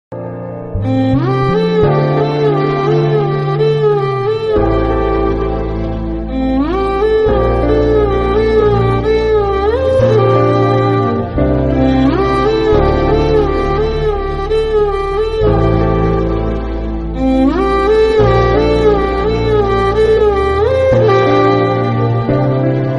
vibrant street-inspired track